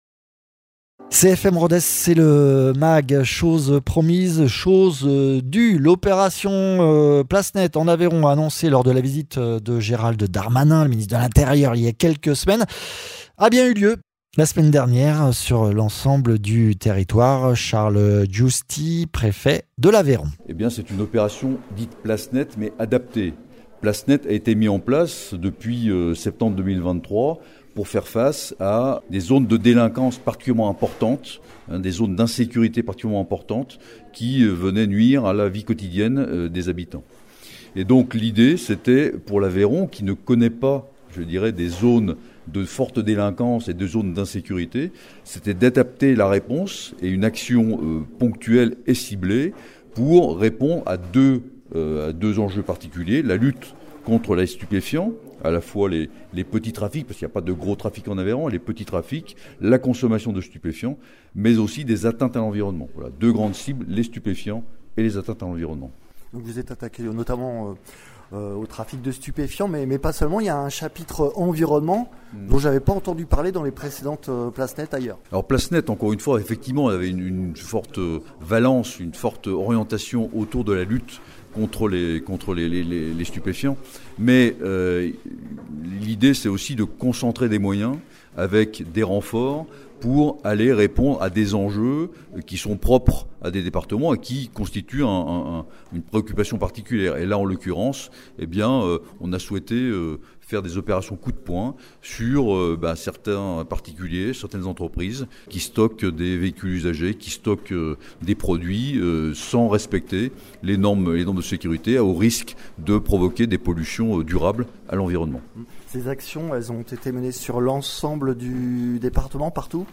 Interviews
Invité(s) : Charles Giusti, Préfet de l’Aveyron ; Colonel Frédéric Brachet, Commandant du groupement de gendarmerie de l’Aveyron